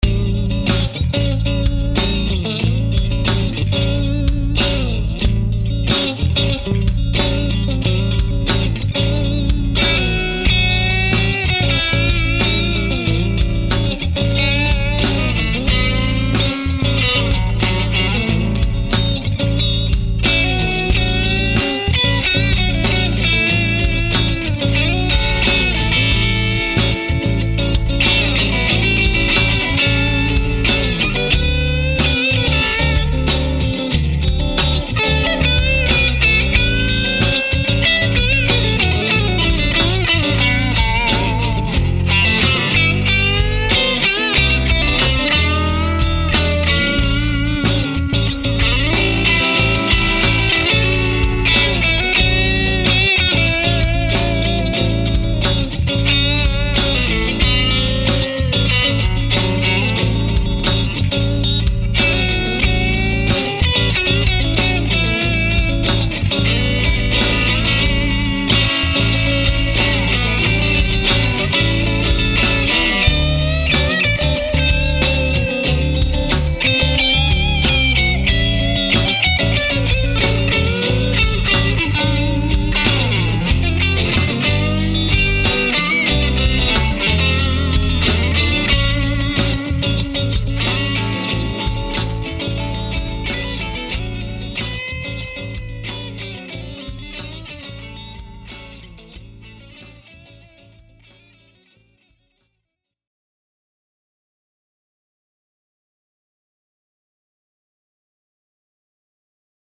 The other day, I was messing around with a dominant seventh ditty in A as I was trying to pick up some improv techniques from Chuck D’Aloia’s Blues with Brains video.
So I switched my KASHA Overdrive on and my jaw dropped!
BTW, both rhythm and lead parts were played with the Kasha overdrive pedal. For the rhythm part, I was in the Classic channel to get that jangly Strat sound from position 2, while I was in the Hot channel for the lead in the neck pickup.